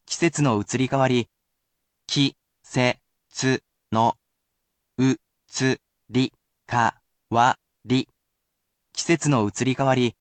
I will begin by providing a new vocabulary word or phrase which I will read aloud for you.